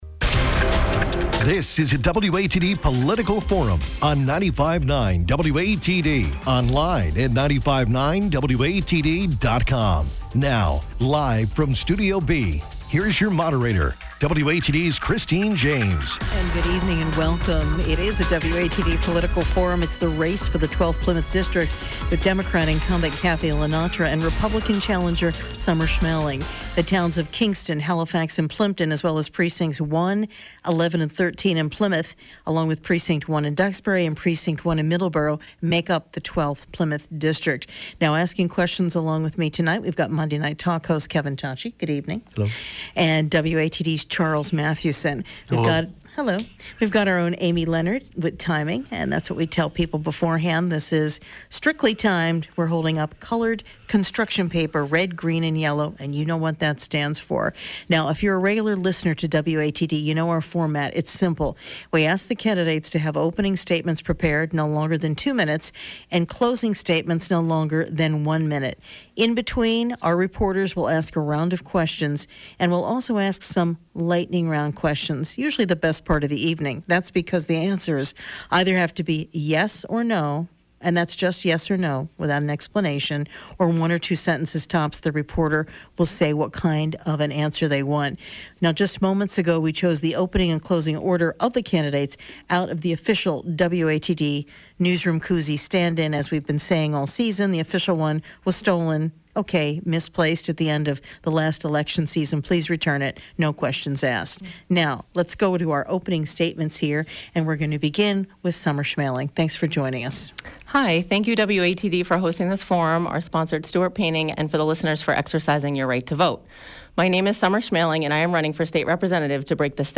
On Monday, September 28th, WATD hosted a live political forum.